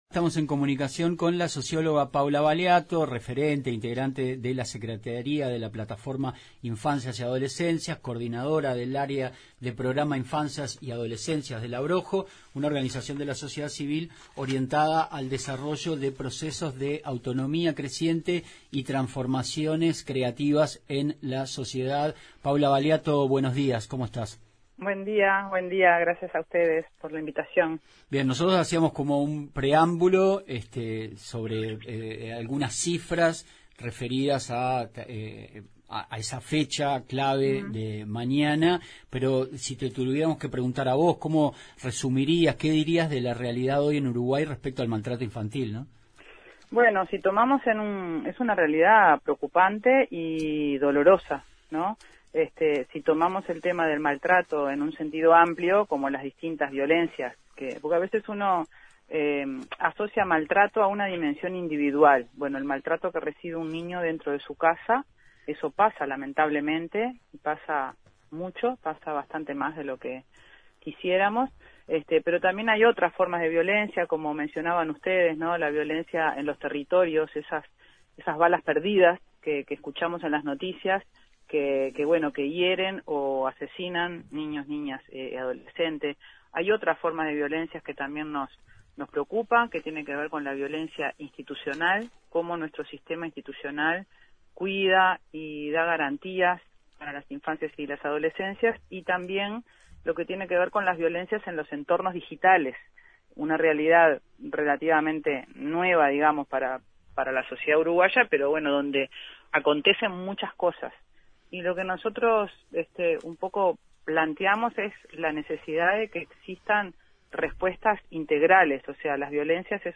En diálogo con La escucha la socióloga